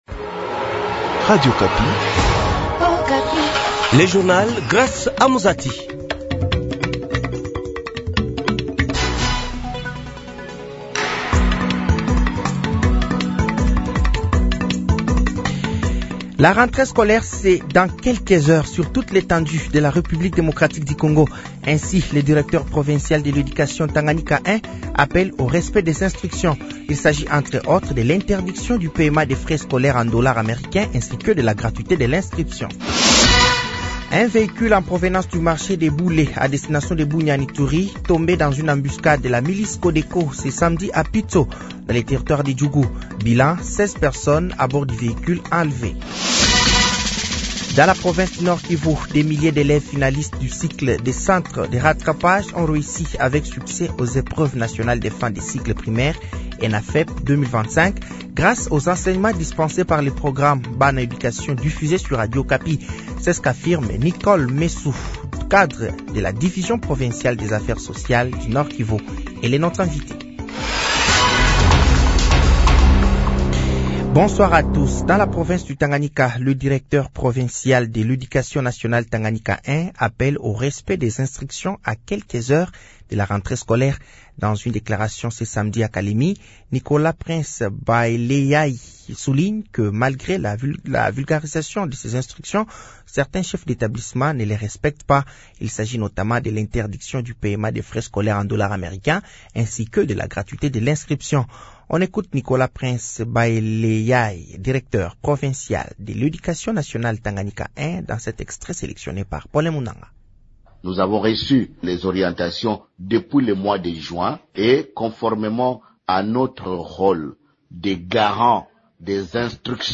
Journal français de 18h de ce dimanche 31 août 2025